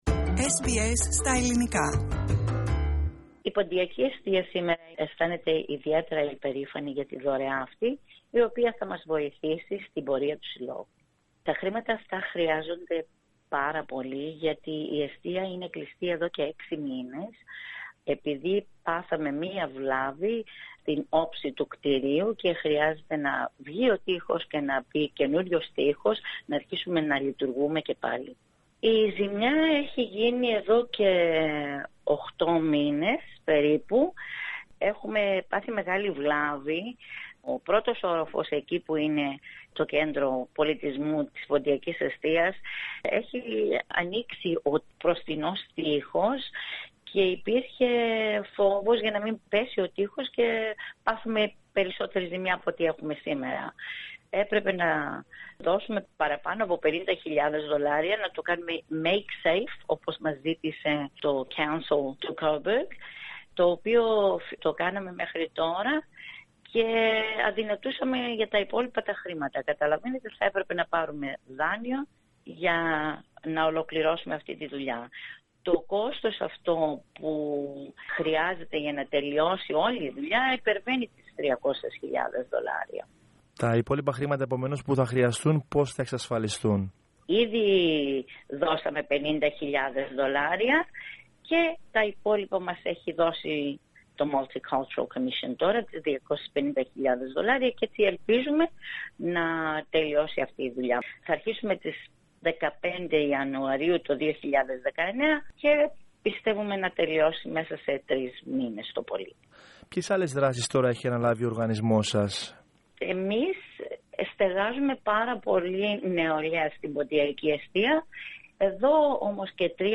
pontiaki_estia_interview.mp3